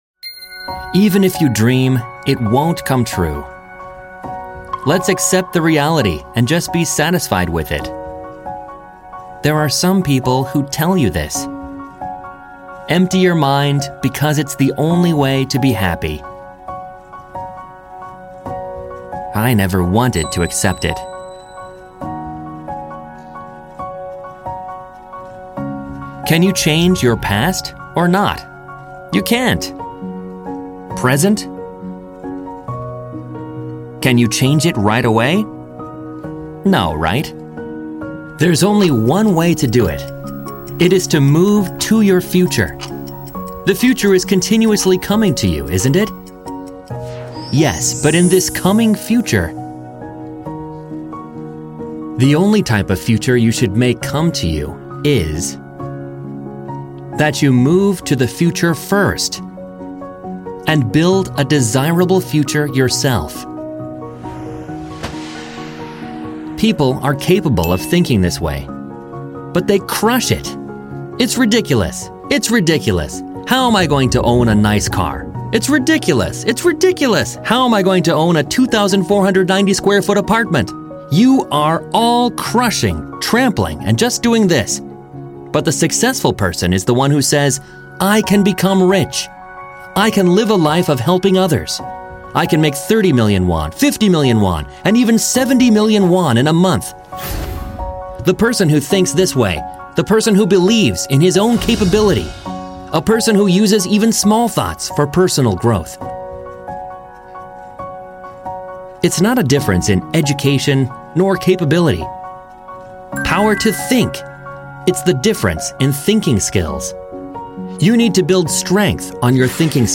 Power to change the future - My Life Scenario (English Dub)